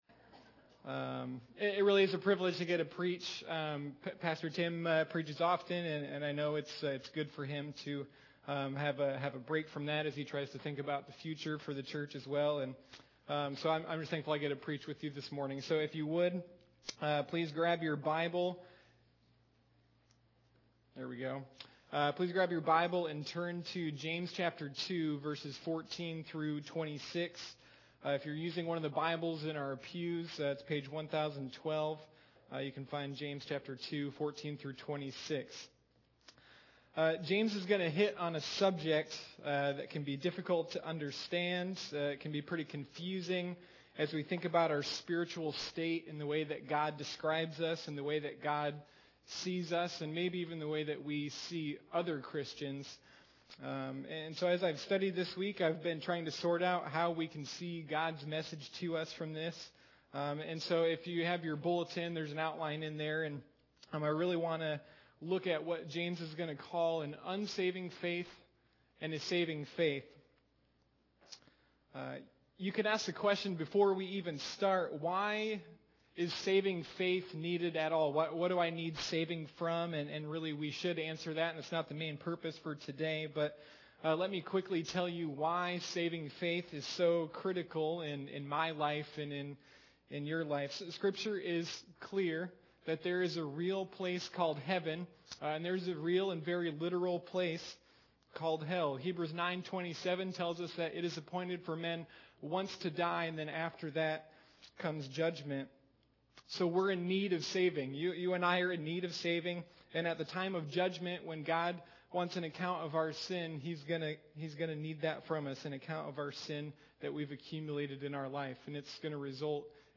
Sunday Morning Living Faith: The Book of James